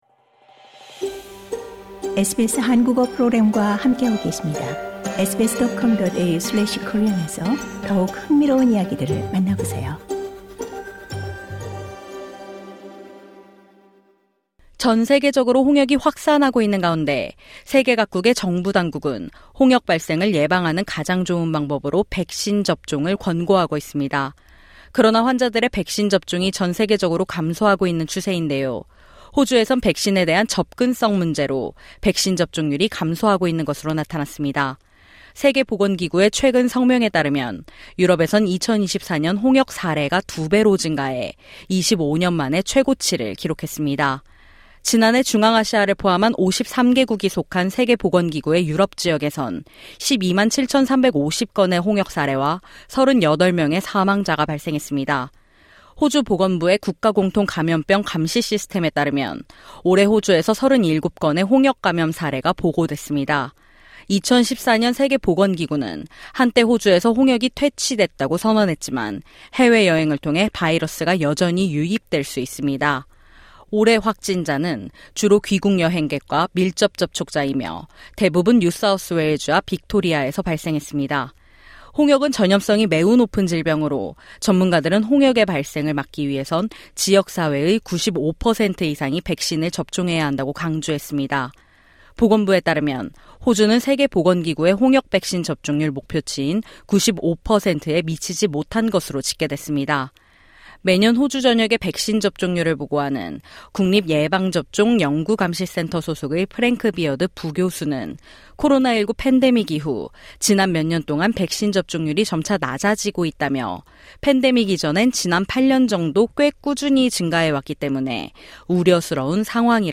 LISTEN TO 씨네챗: ‘델마와 루이스’, 여성 투톱의 클래식 로드무비 SBS Korean 05:20 Korean 상단의 오디오를 재생하시면 뉴스를 들으실 수 있습니다.